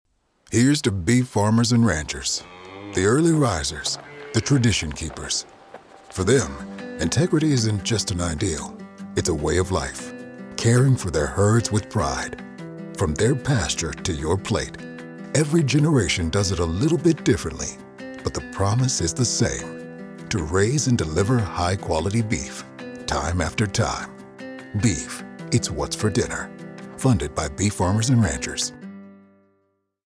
National Radio Ads